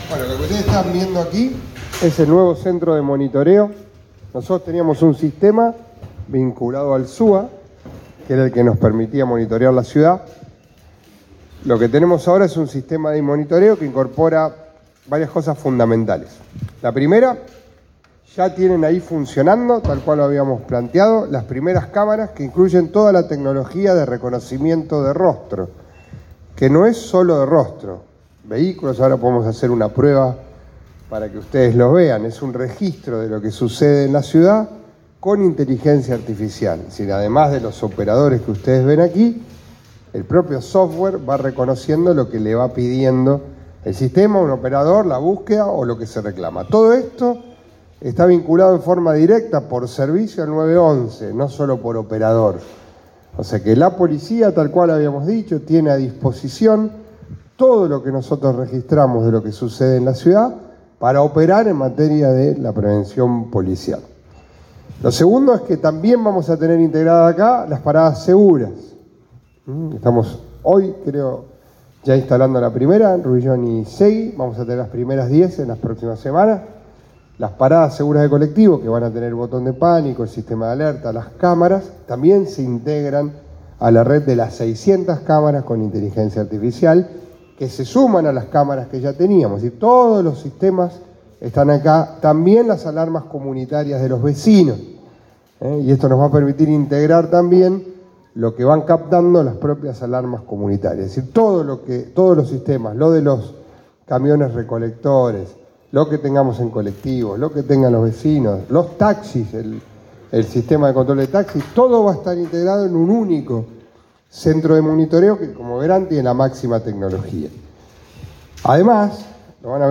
Javkin-conferencia-de-prensa.mp3